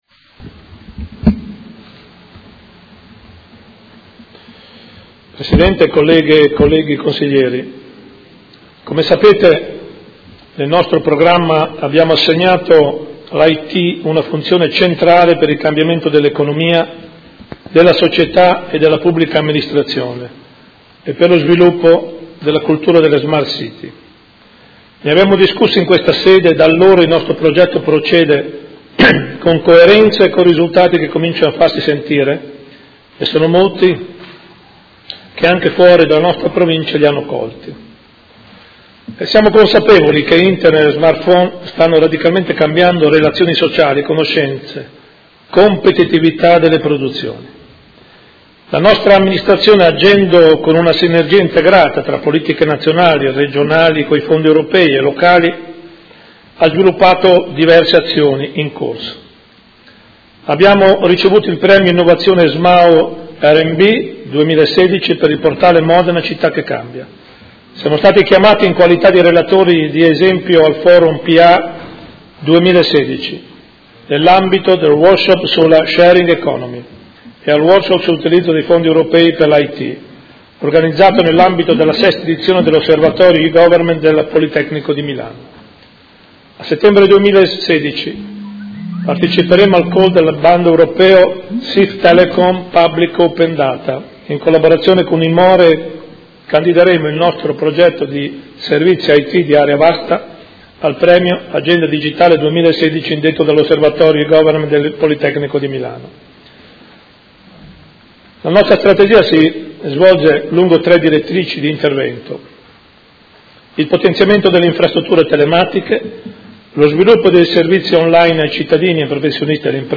Seduta del 22/09/2016 Comunicazioni del Sindaco sulle smart city